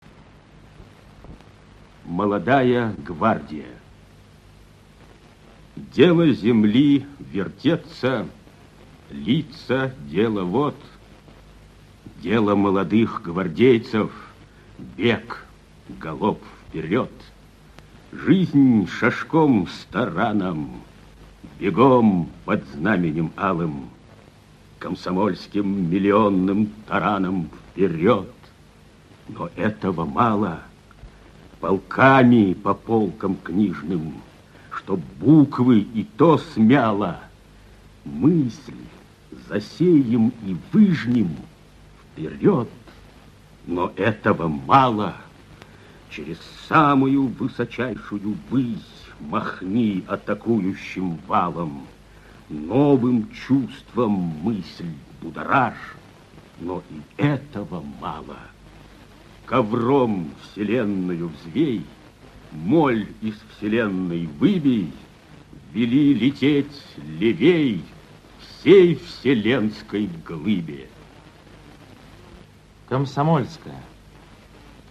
Прослушивание аудиозаписи стихотворения «Молодая гвардия» с сайта «Старое радио». Исполнитель Е. Киндинов.